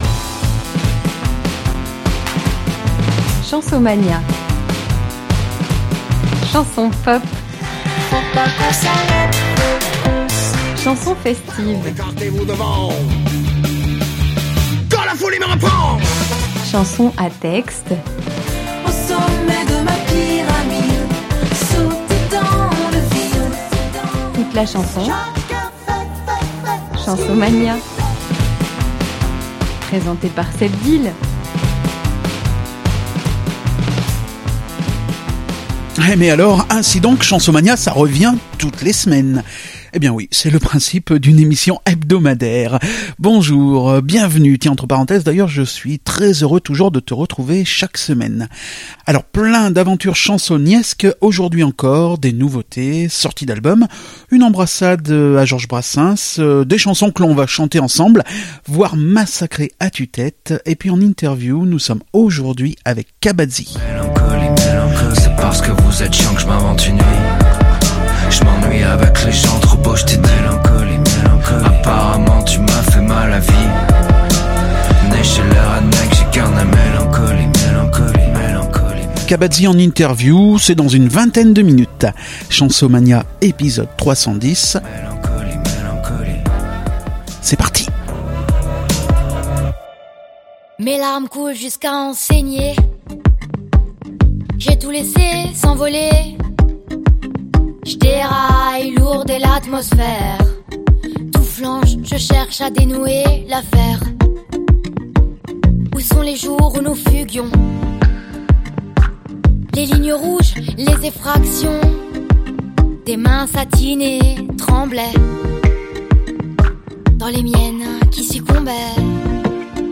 Dans cet épisode 310 de Chansomania, Cabadzi en interview, à l'occasion de la sortie de l'album "Bürrhus", dispo depuis le 5 mars.